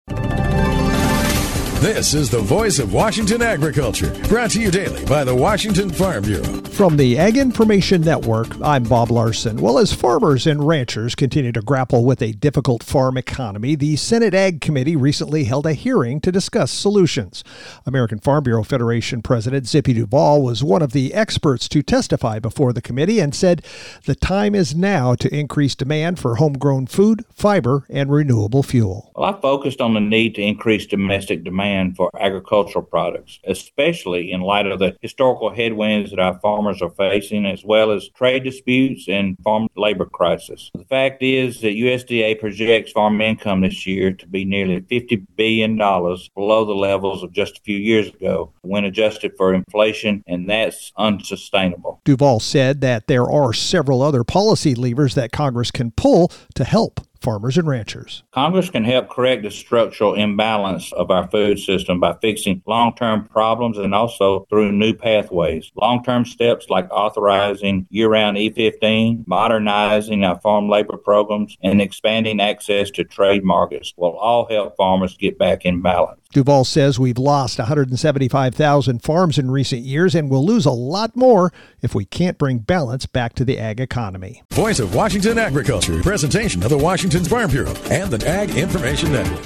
Thursday Mar 12th, 2026 58 Views Washington State Farm Bureau Report